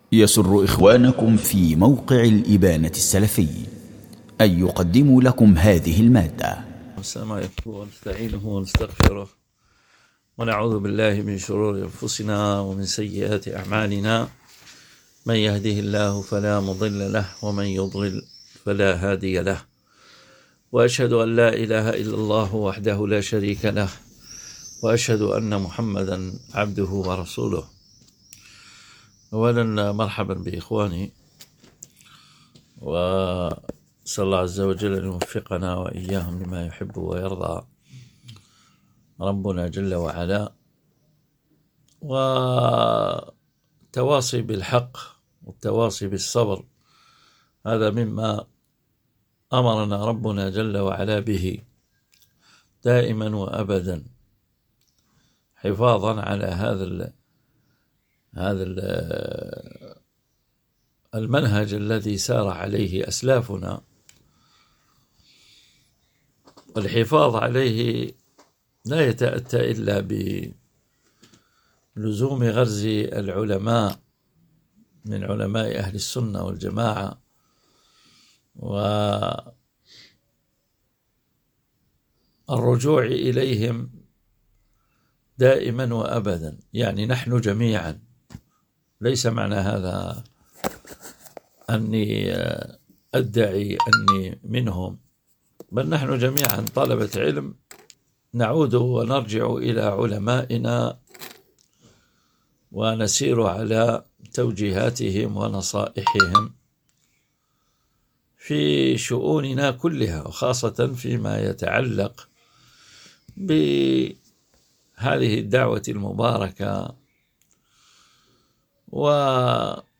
دروس ومحاضرات